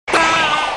Hillary The Parrot Screaming SFX